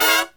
FALL HIT07-L.wav